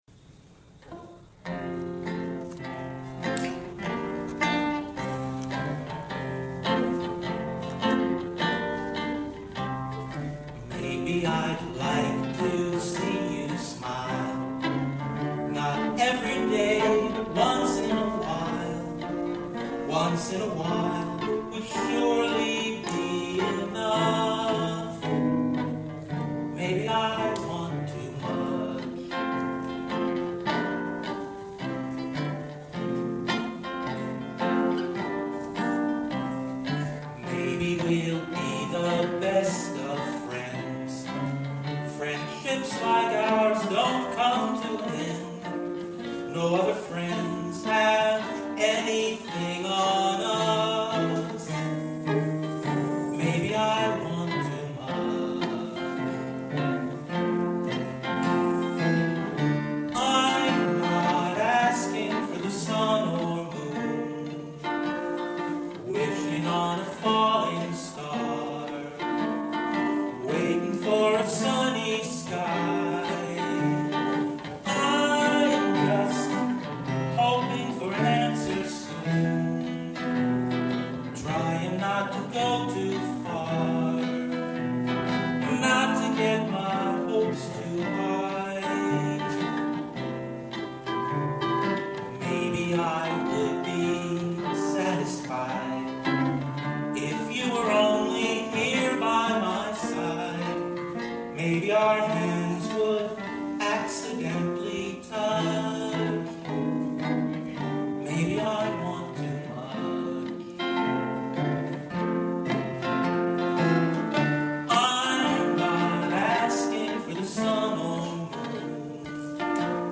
Maybe I Want Too Much written in 2008, this performance is from an October 2011 Talent Show at Wilson Memorial Church in Watchung, NJ